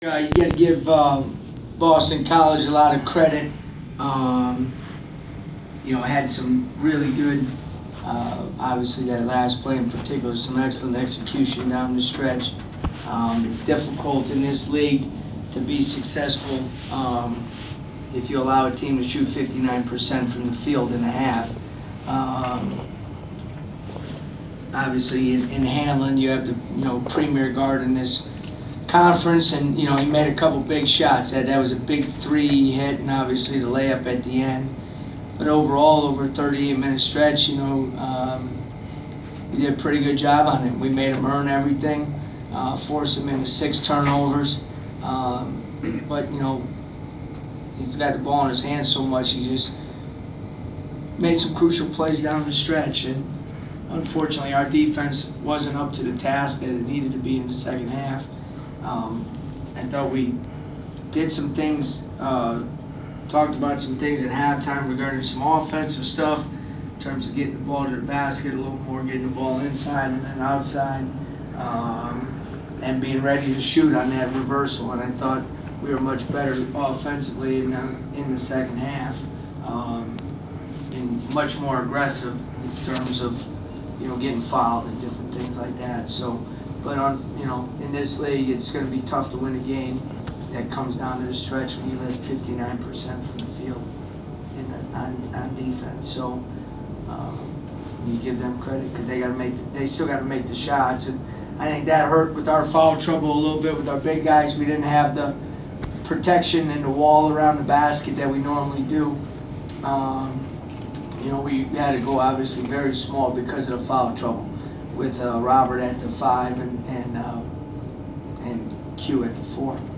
We attended the post-game presser of Georgia Tech men’s basketball head coach Brian Gregory following his team’s 64-62 home defeat to Boston College on Jan. 25. Topics included the Yellow Jackets still seeking their first ACC win, struggling with foul trouble throughout contest and improvements his team has made through the season.